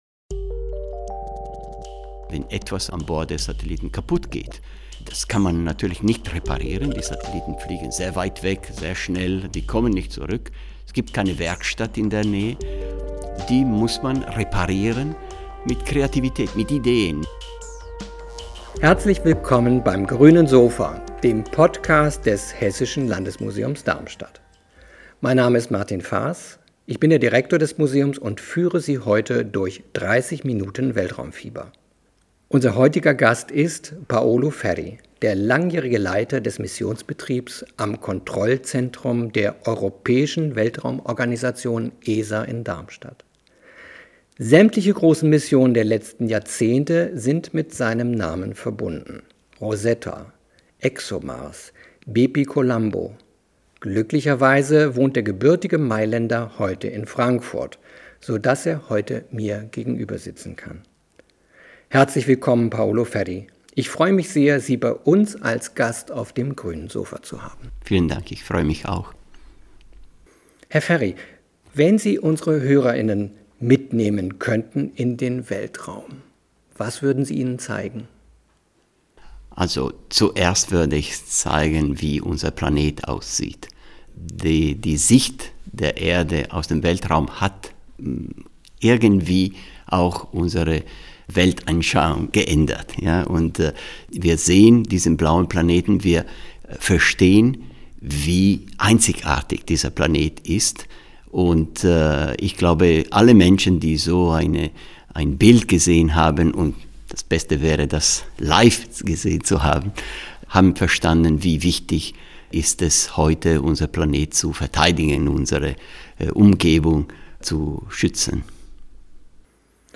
Seine Begeisterung, seine Liebe zum Beruf und zum Weltall ist hörbar!